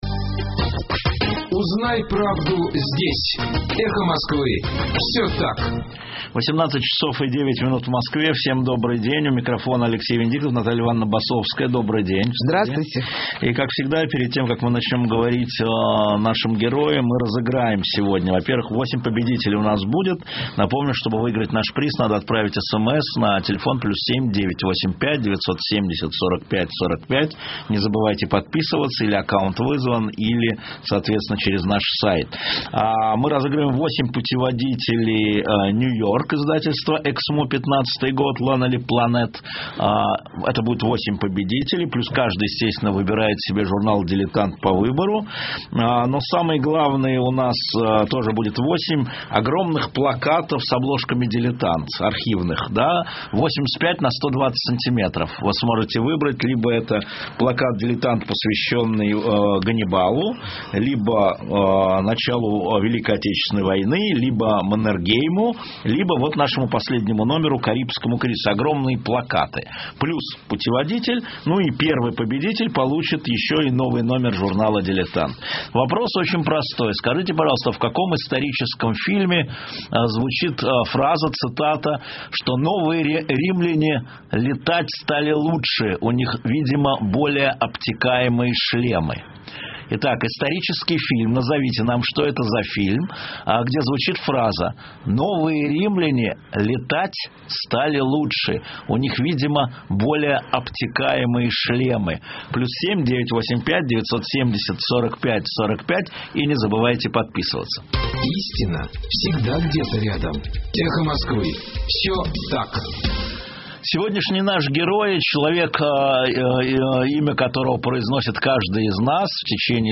В эфире радиостанции «Эхо Москвы» - Наталья Басовская, профессор РГГУ.